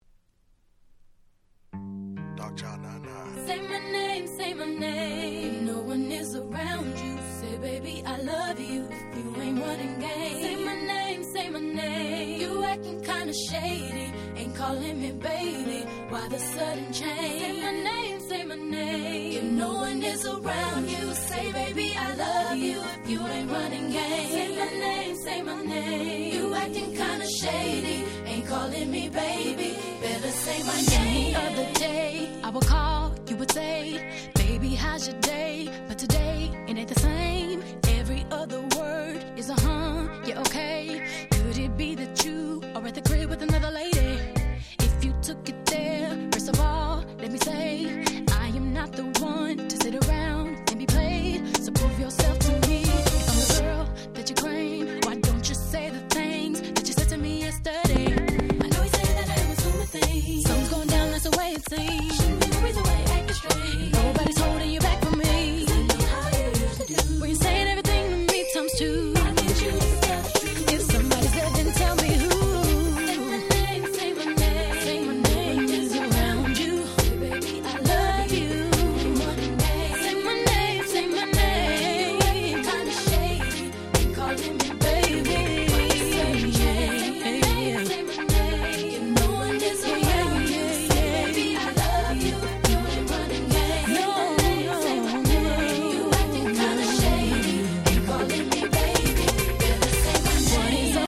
99' Super Hit R&B !!